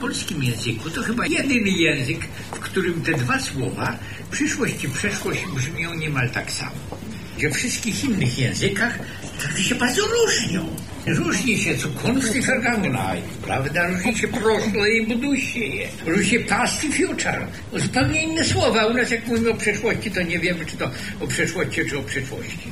Dzisiaj (18 marca) odbyła się 13. edycja Ogólnopolskiej Konferencji Samorządu i Oświaty ,,Edukacja Przyszłości”.
Szczególnym punktem sesji było wystąpienie polonisty i językoznawcy – frof. Jerzego Bralczyka, który mówił o tym, skąd biorą się nazwy i jak wpływają one na nasze myślenie: